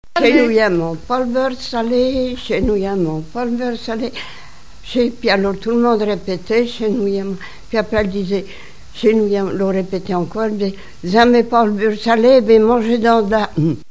danse : branle : courante, maraîchine
Pièce musicale inédite